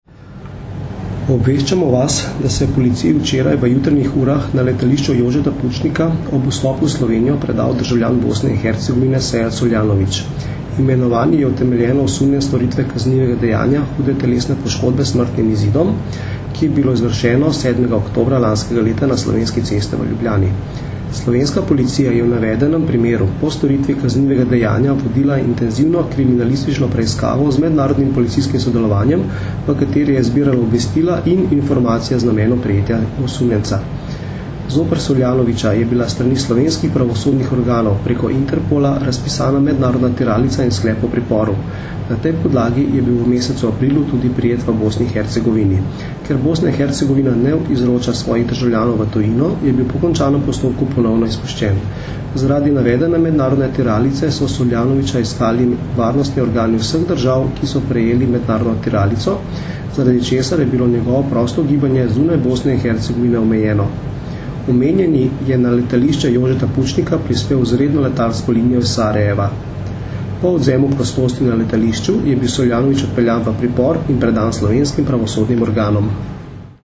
Zvočni posnetek obvestila